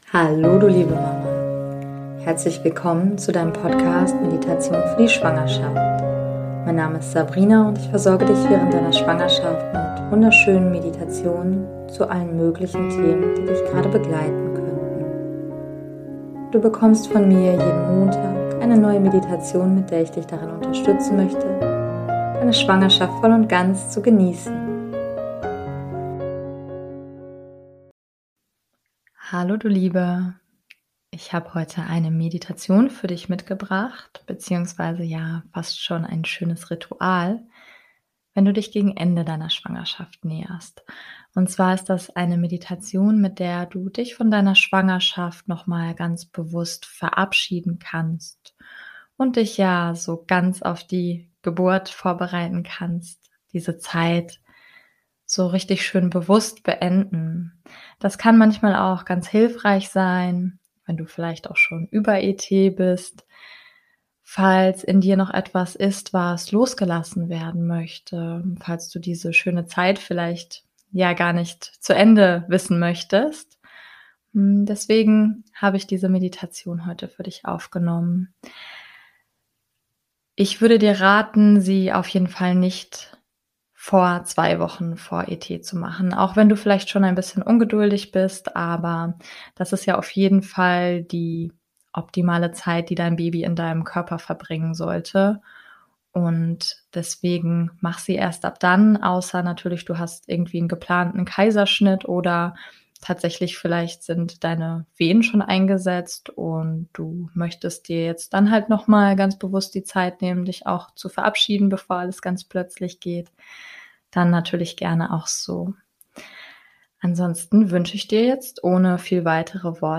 #043 - Meditation Abschied nehmen von der Schwangerschaft ~ Meditationen für die Schwangerschaft und Geburt - mama.namaste Podcast